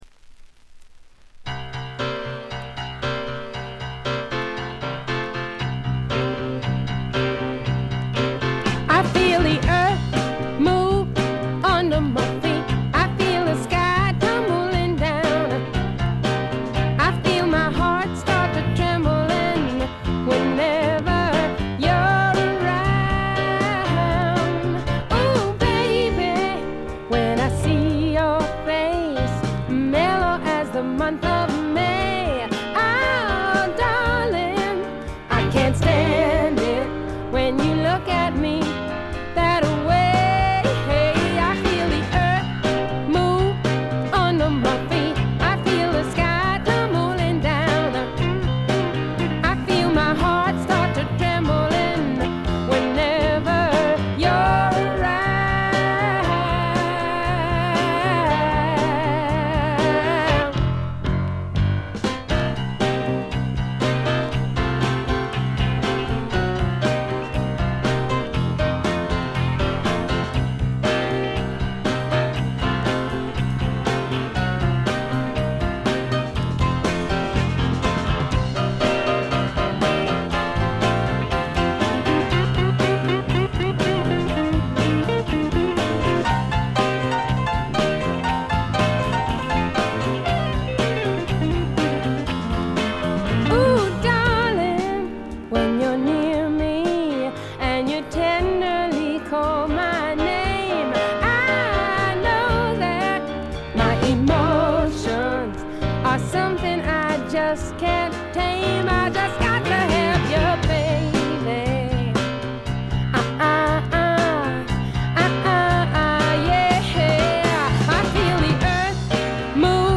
全体にバックグラウンドノイズ、チリプチ多め大きめ。A1エンディングからA2冒頭にかけて周回ノイズ。
試聴曲は現品からの取り込み音源です。
A1-A2連続です。曲間のノイズご確認ください。